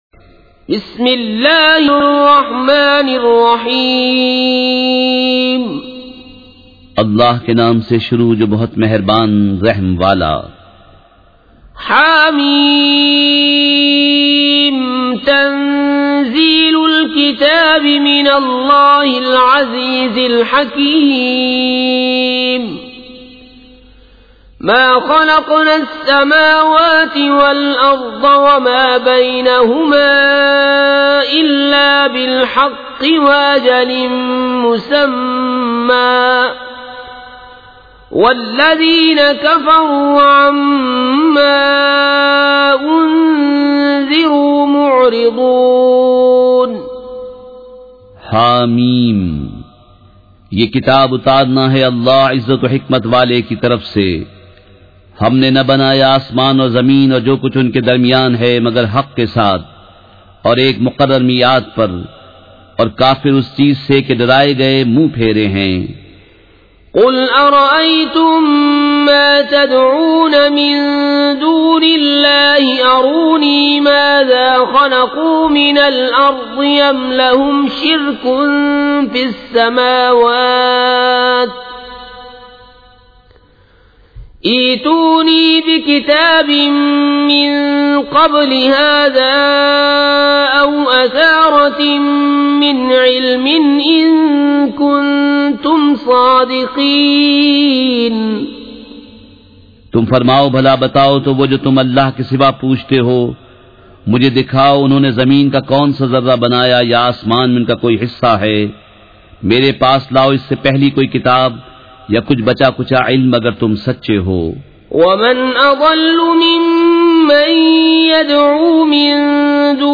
سورۃ الاحقاف مع ترجمہ کنزالایمان ZiaeTaiba Audio میڈیا کی معلومات نام سورۃ الاحقاف مع ترجمہ کنزالایمان موضوع تلاوت آواز دیگر زبان عربی کل نتائج 1787 قسم آڈیو ڈاؤن لوڈ MP 3 ڈاؤن لوڈ MP 4 متعلقہ تجویزوآراء